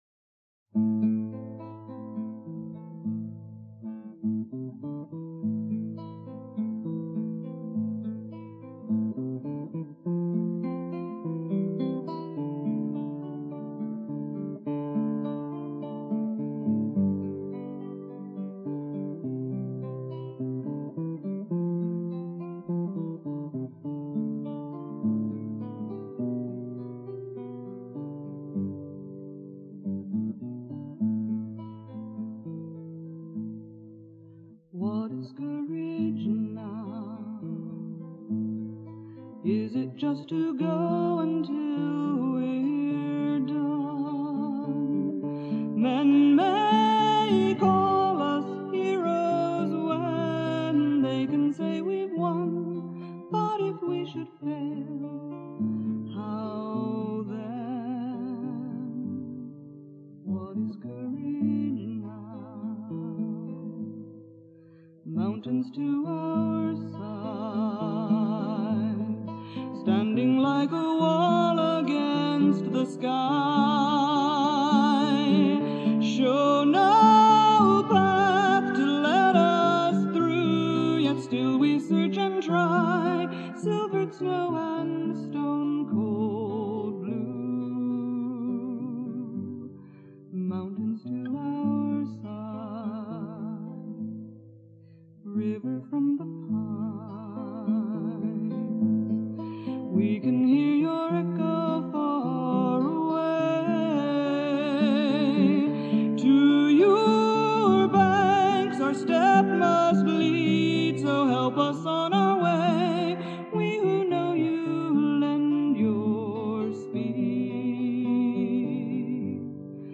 Performed 2/6/98 in Palo Alto, California.)